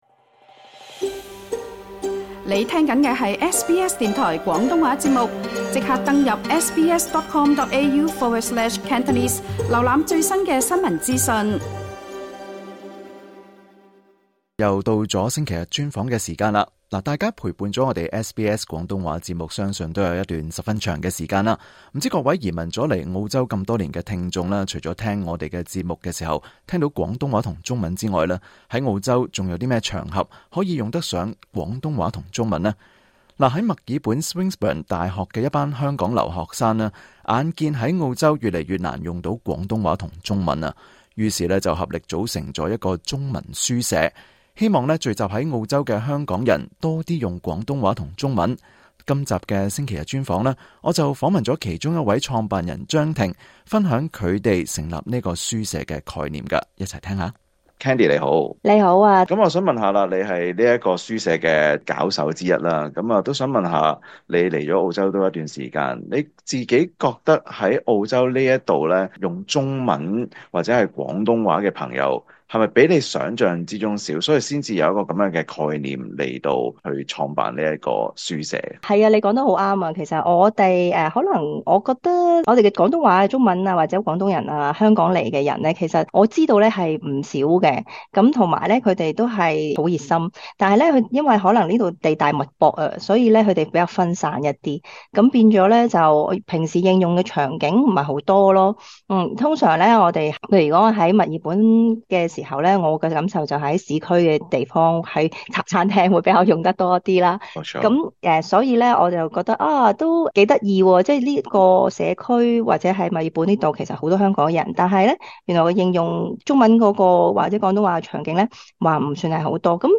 今集【星期日專訪】，我們訪問了在墨爾本Swinburne大學的一班香港留學生，為了希望聚集更多在澳洲的香港人，能夠多些應用廣東話及中文，合力在大學校園的一間由香港人開設的咖啡店內，創立一個中文書舍，希望能將廣東話及中文世代相傳。